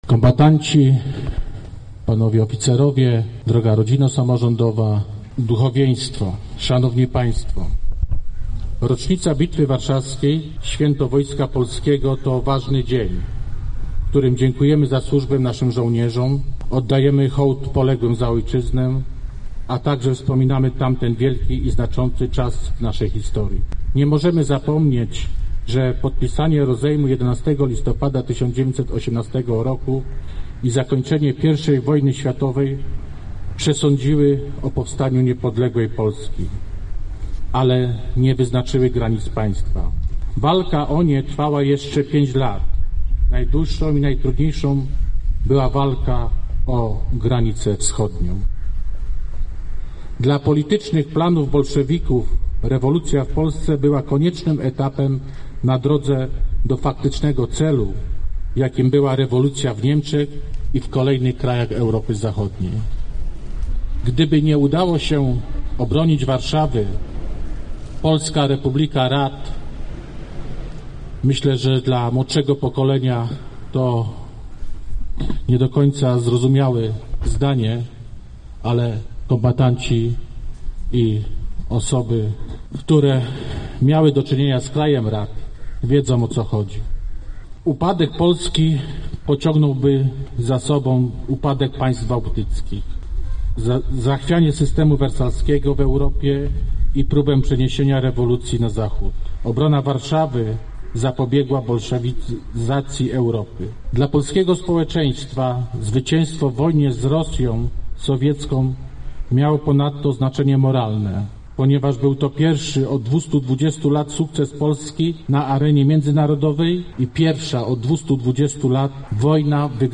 Tradycyjnie od mszy za ojczyznę w kościele pw. św. Józefa Oblubieńca NMP rozpoczęły się wieluńskie obchody Święta Wojska Polskiego. Następnie poczty sztandarowe i zgromadzeni goście przeszli pod tablicę Marszałka Józefa Piłsudskiego, gdzie miały miejsce okolicznościowe wystąpienia.